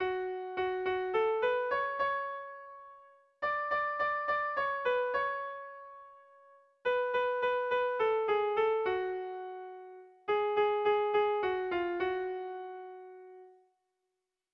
Bertso melodies - View details   To know more about this section
Musical structure
AB